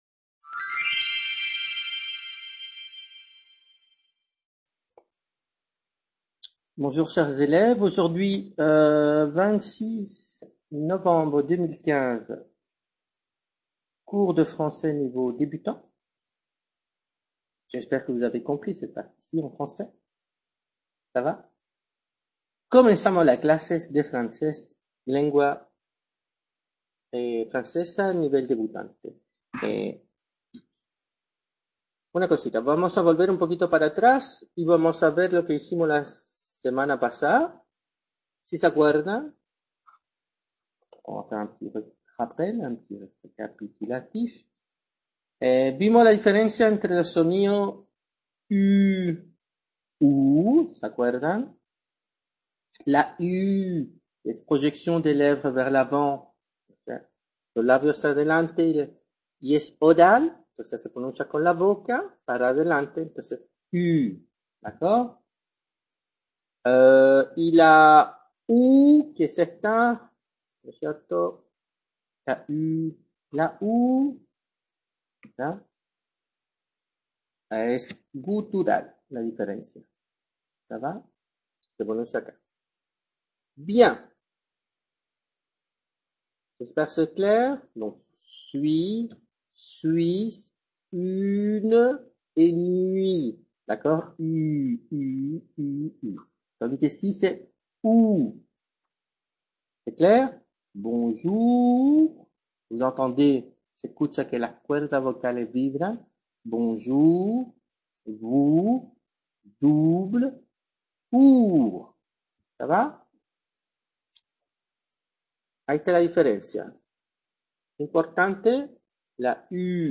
Clase de Francés Nivel Debutante 26/11/2015 | Repositorio Digital